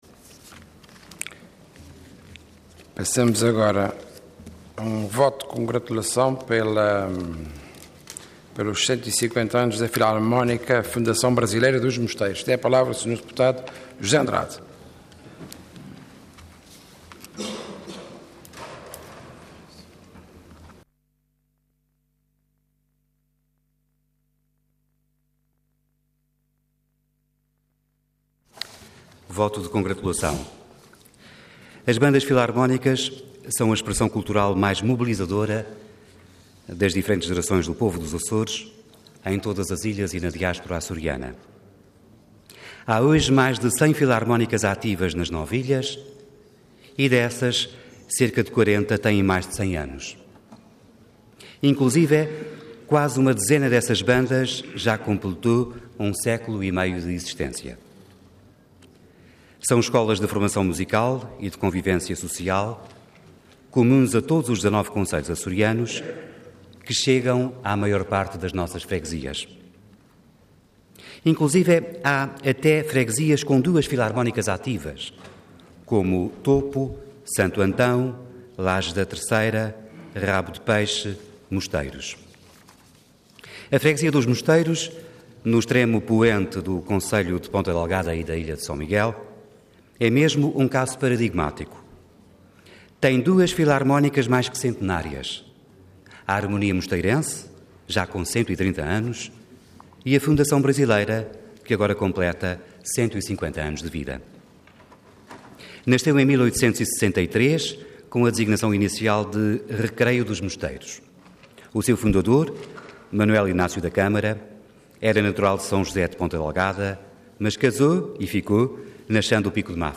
Detalhe de vídeo 10 de julho de 2013 Download áudio Download vídeo Diário da Sessão Processo X Legislatura 150 Anos da Filarmónica "Fundação Brasileira" dos Mosteiros. Intervenção Voto de Congratulação Orador José Andrade Cargo Deputado Entidade PSD